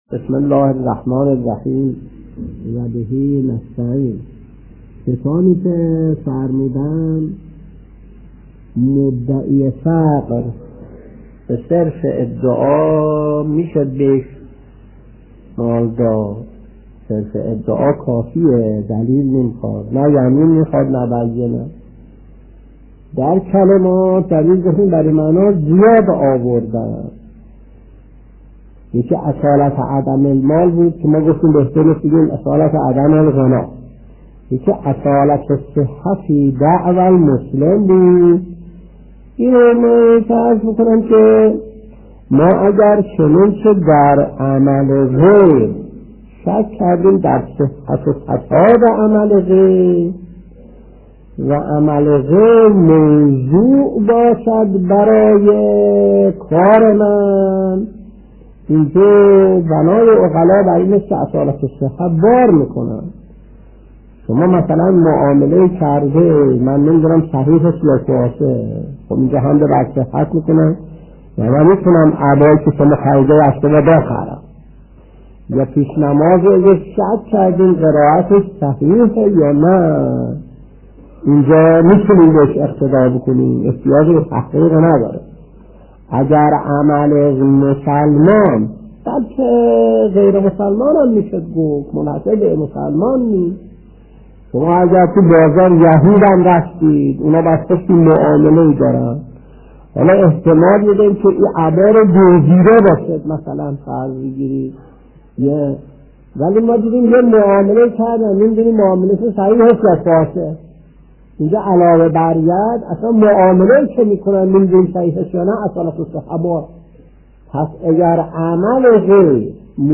سلسله درس های زکات : درس 322 : (13/8/1363)